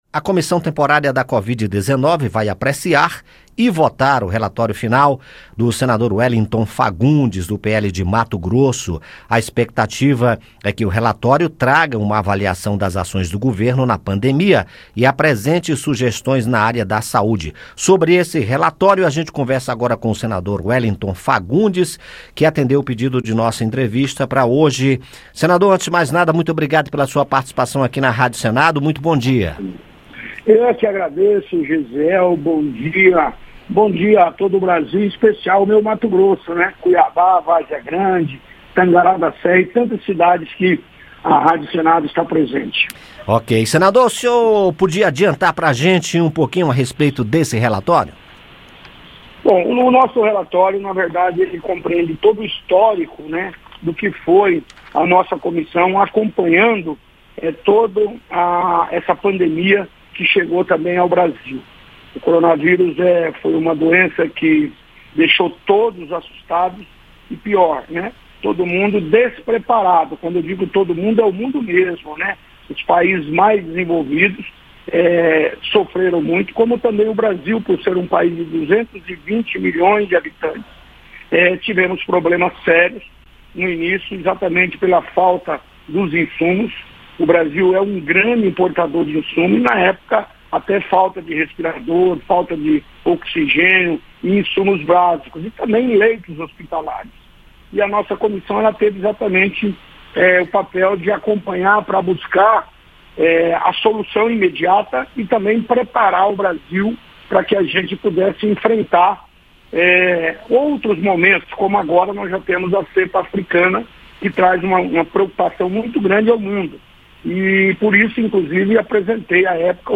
A expectativa é que o documento traga uma avaliação das ações do governo na pandemia e apresente sugestões na área da saúde. O senador conversou com a Rádio Senado sobre as conclusões do relatório. Ouça a entrevista.